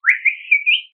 自然の響きを模した、犬や他の動物を呼ぶときに用いられる口笛の音。